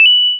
coin2.wav